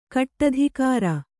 ♪ kaṭṭadhikāra